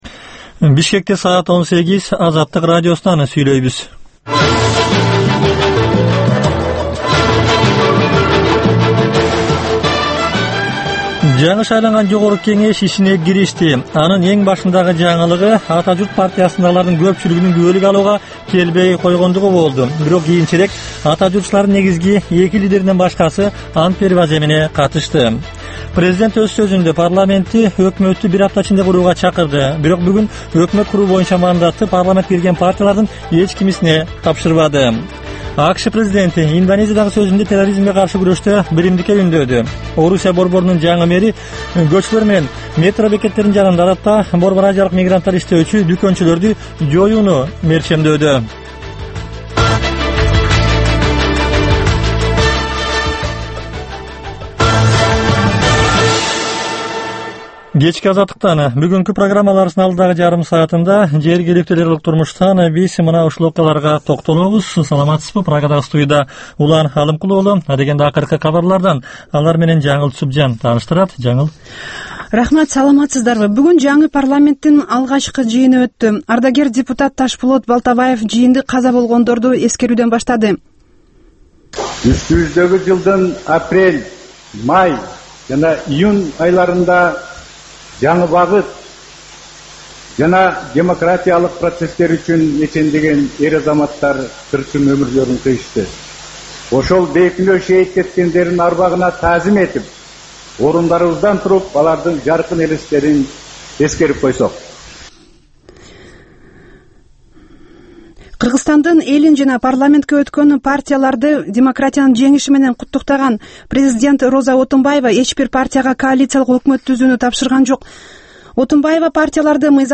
Кечки 6дагы кабарлар
"Азаттык үналгысынын" күндөлүк кабарлар топтому Ала-Тоодогу, Борбордук Азиядагы жана дүйнөдөгү эң соңку жаңылыктардан турат. Кабарлардын бул топтому «Азаттык үналгысынын» оригиналдуу берүүсү обого чыккан сааттардын алгачкы беш мүнөтүндө сунушталат.